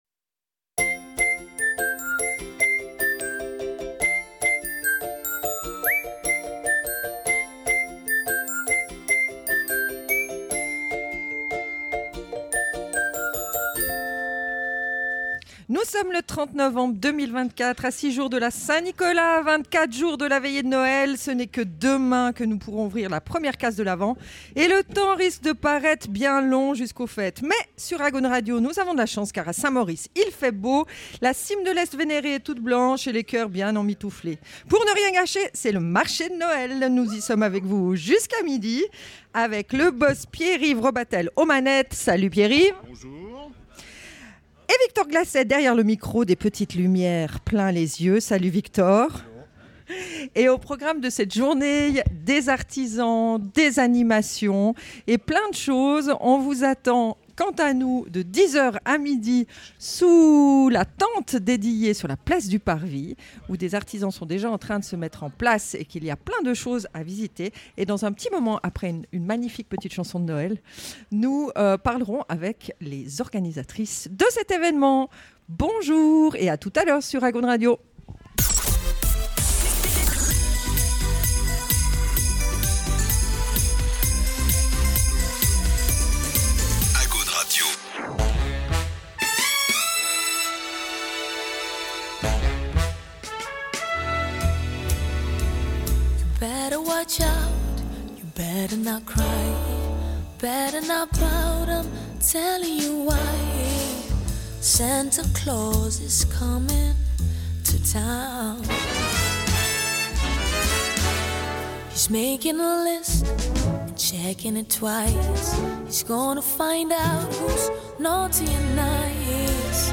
Les interviews réalisés lors de notre Spécial Marché de Noël du samedi 30 novembre 2024.